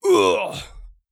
ZS被击倒1.wav
ZS被击倒1.wav 0:00.00 0:01.13 ZS被击倒1.wav WAV · 97 KB · 單聲道 (1ch) 下载文件 本站所有音效均采用 CC0 授权 ，可免费用于商业与个人项目，无需署名。
人声采集素材/男3战士型/ZS被击倒1.wav